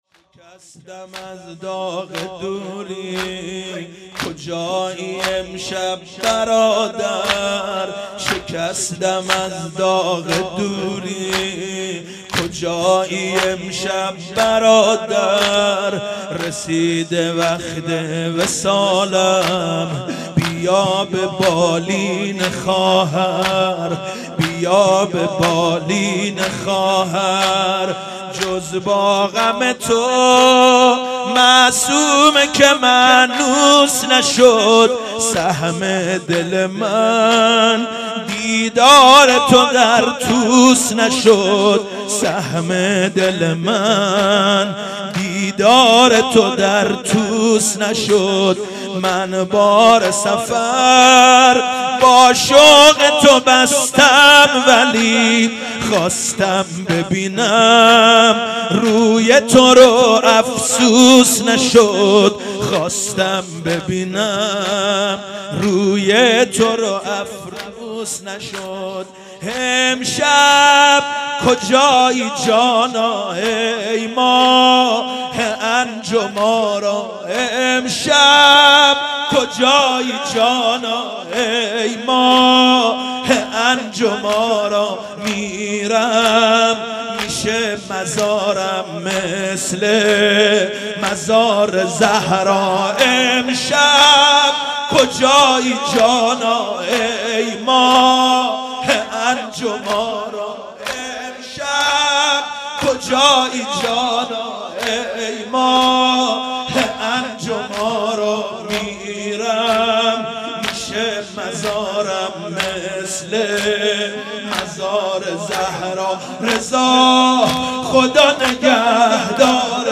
صوت مرثیه‌سرایی
مداح اهل بیت(ع)